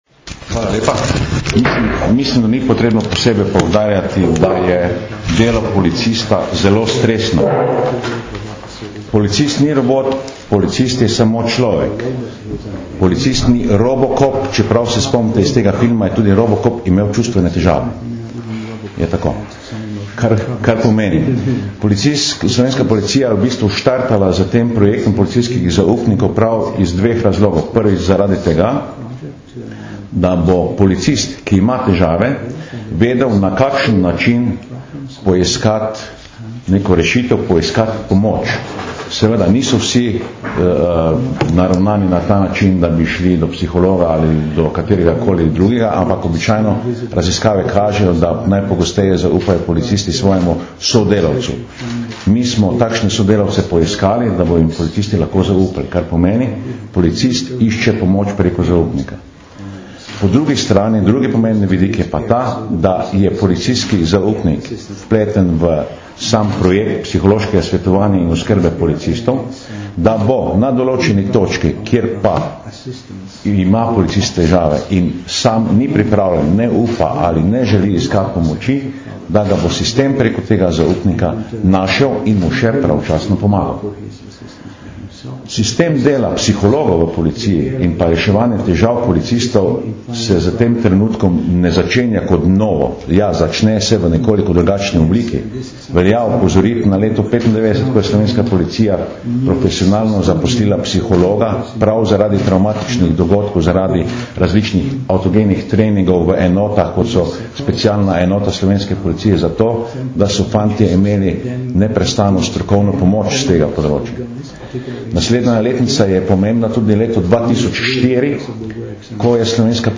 Policija - Psihološka pomoč in zaščita policistov - informacija z novinarske konference